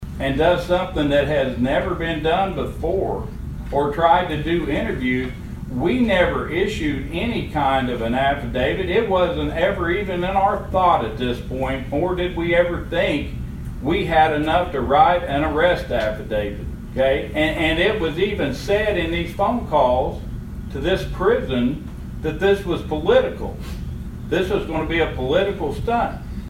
Osage County Sheriff Eddie Virden held a press conference on Tuesday in response to comments made by District Attorney Mike Fisher on Monday.